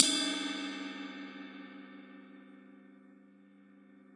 Crash Ride Cymbal24in Paiste Vintage " CRC24in Pai Bl~v10
描述：用MXL 603近距离话筒和两个Peavey驻极体电容话筒在XY对中录制的24英寸直径的Paiste 1960年代复古CrashRide镲片的1张照片样本。
标签： 多重采样 1次 速度
声道立体声